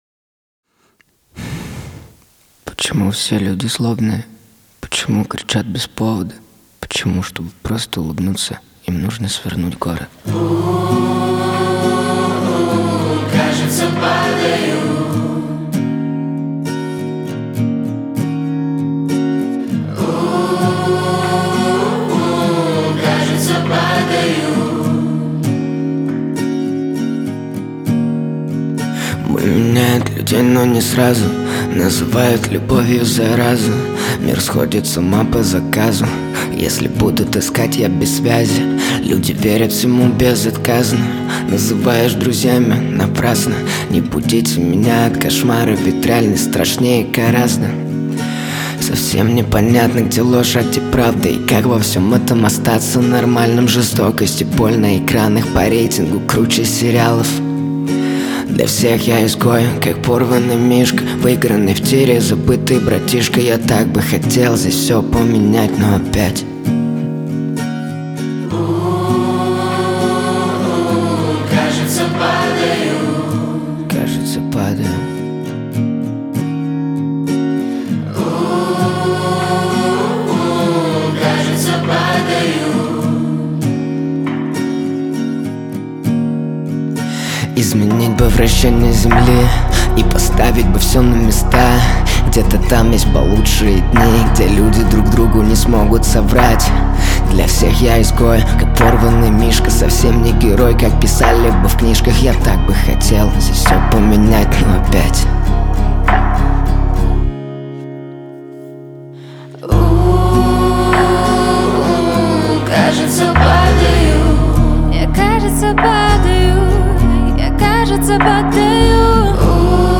Русские и украинские песни